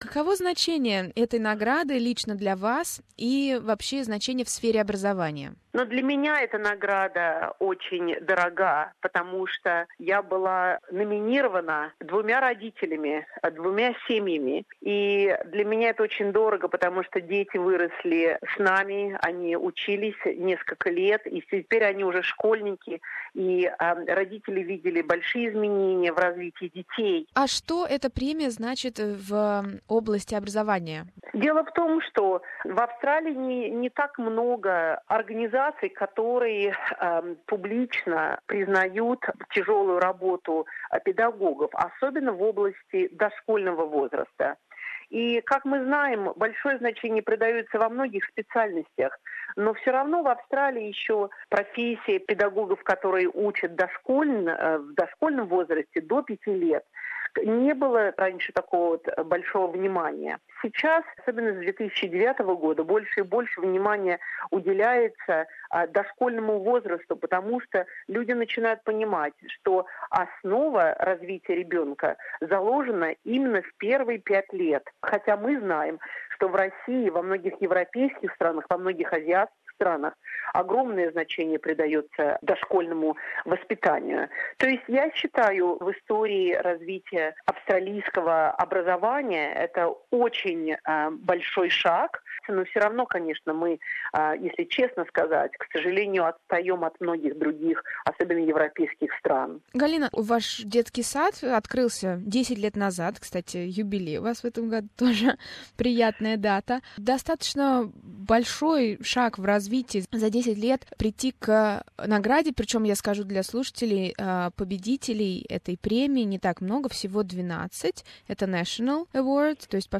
В интервью она рассказала об инновационных образовательных программах для дошкольников.